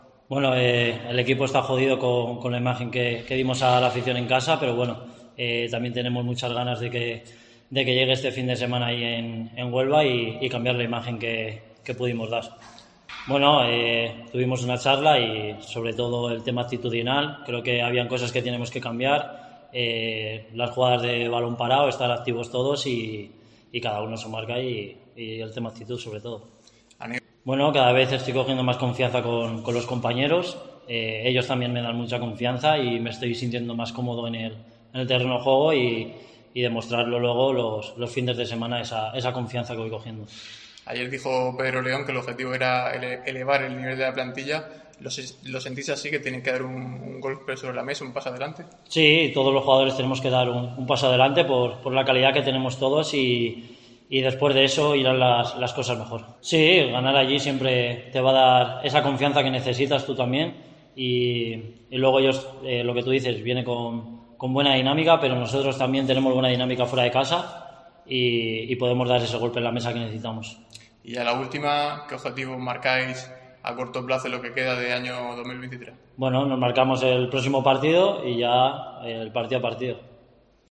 comparecencia de prensa